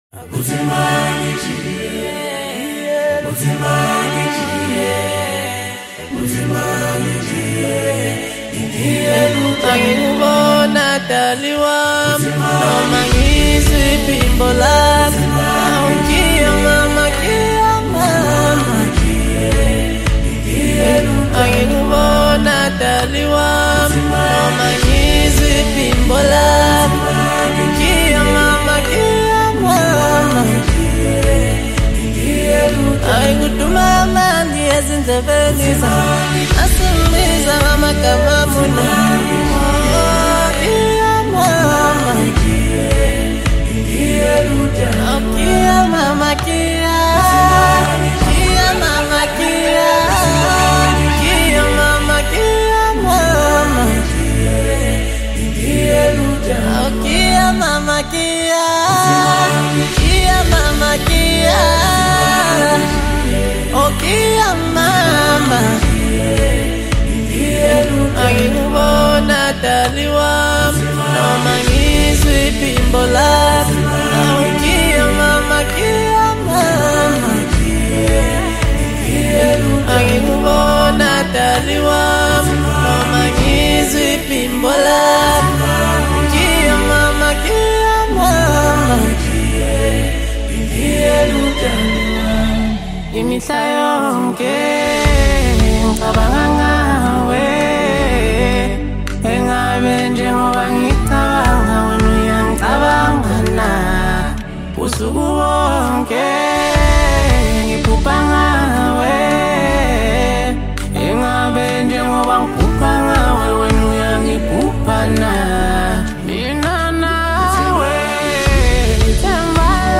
AfropopAudioSouth African Music